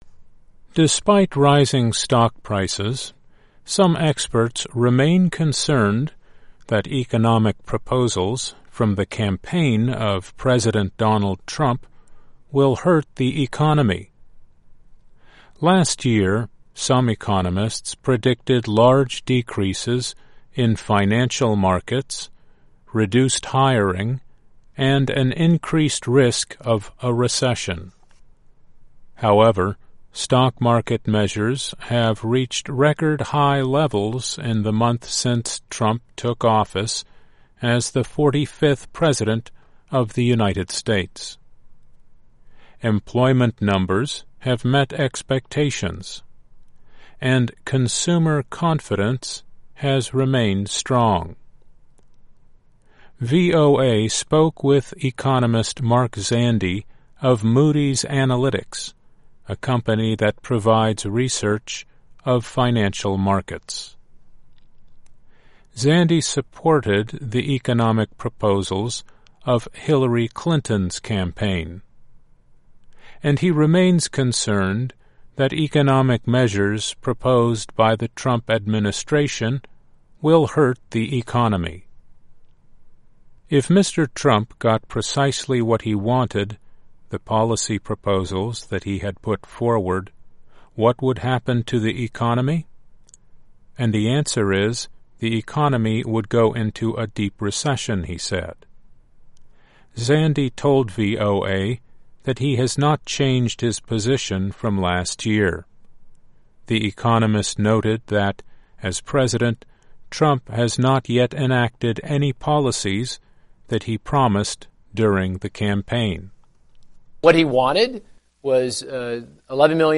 ·On-line English TV ·English publication ·broadcasting station ·Classical movie ·Primary English study ·English grammar ·Commercial English ·Pronunciation ·Words ·Profession English ·Crazy English ·New concept English ·Profession English ·Free translation ·VOA News ·BBC World News ·CNN News ·CRI News ·English Songs ·English Movie ·English magazine